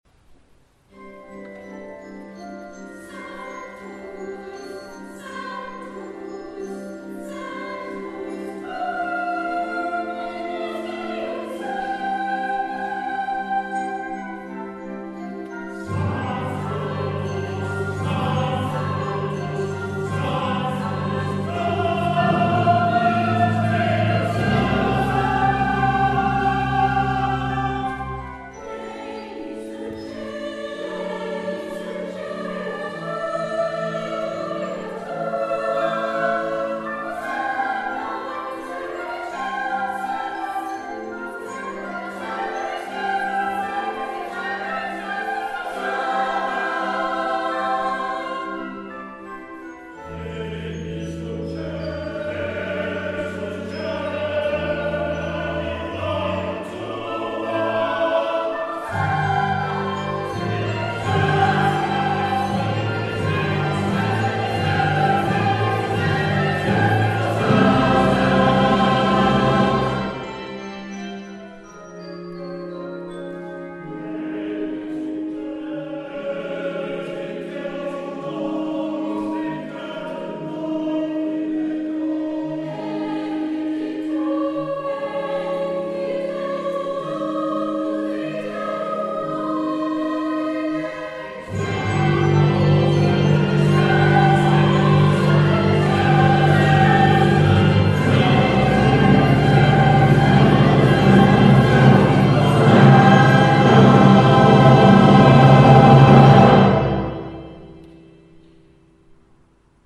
From the Requiem by John Rutter, performed at the Simon Balle Choral Concert 2017